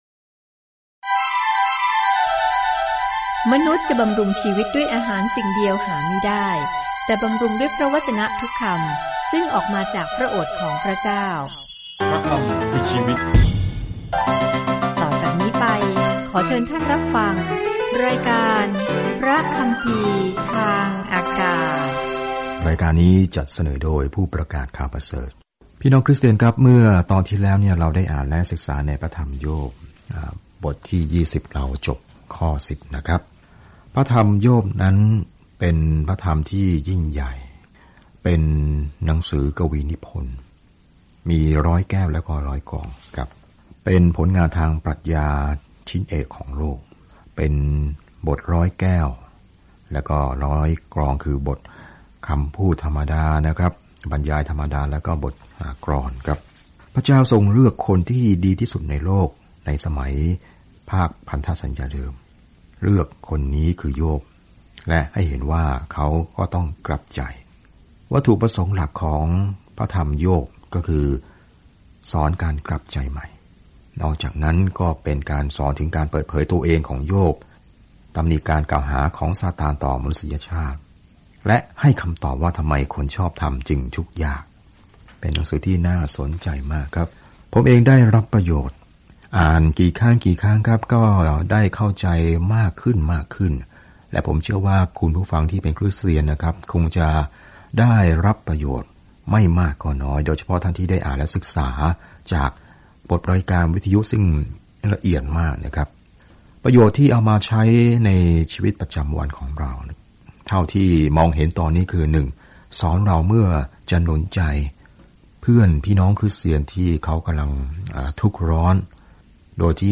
ในละครสวรรค์และโลกนี้ เราได้พบกับโยบ คนดีที่พระเจ้ายอมให้ซาตานโจมตี ทุกคนมีคำถามมากมายว่าทำไมเรื่องเลวร้ายจึงเกิดขึ้น เดินทางผ่านงานทุกวันในขณะที่คุณฟังการศึกษาด้วยเสียงและอ่านข้อที่เลือกจากพระวจนะของพระเจ้า